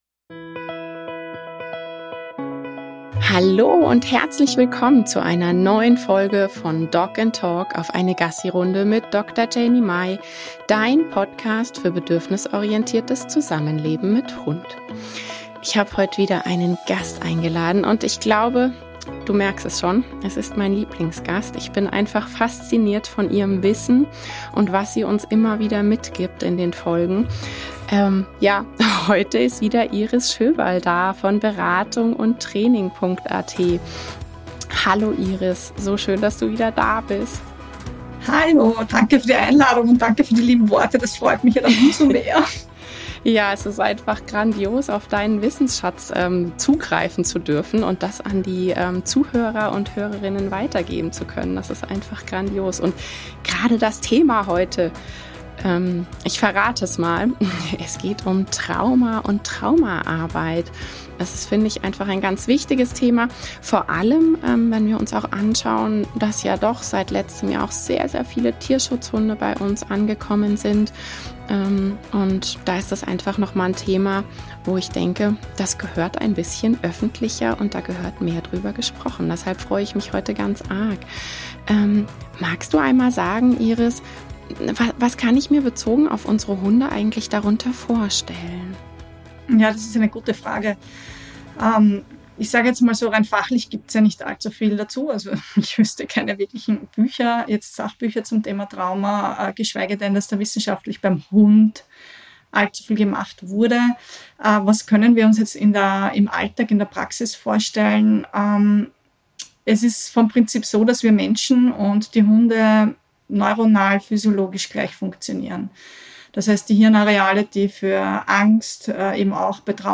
#23 - Traumaarbeit beim Hund - Interview